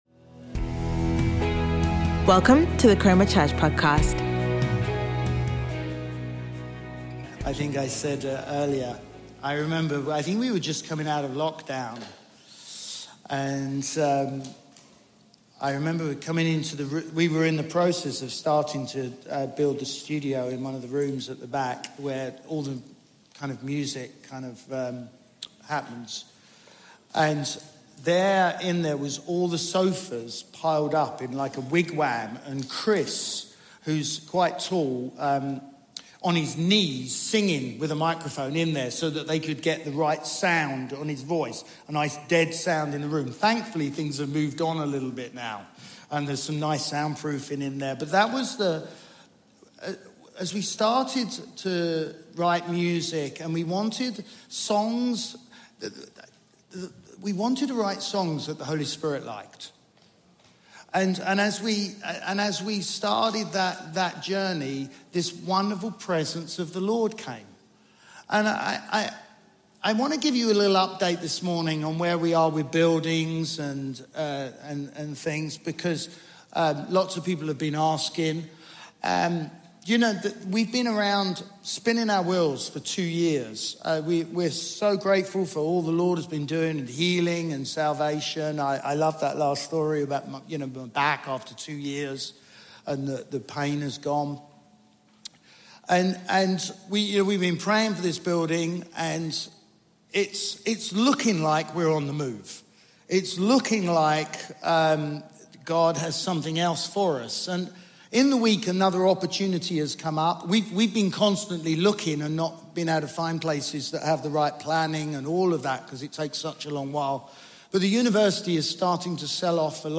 Chroma Church - Sunday Sermon Lets Finish The Job!